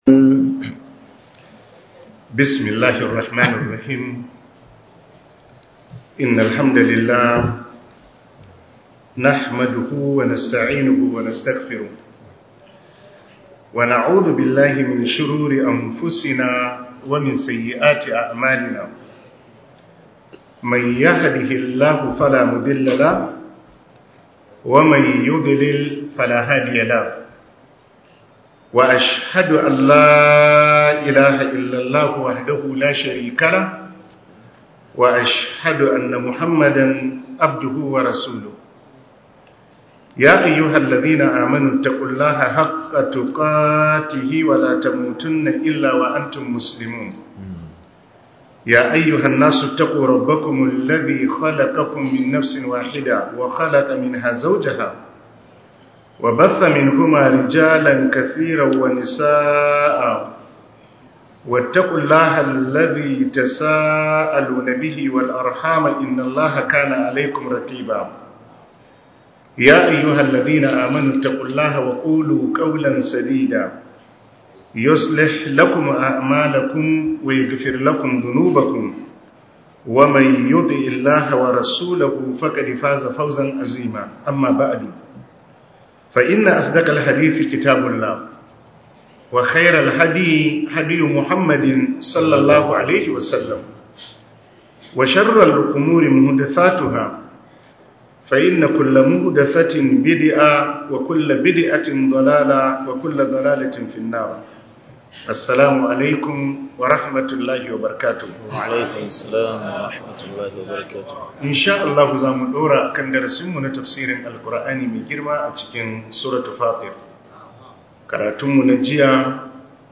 Tafsir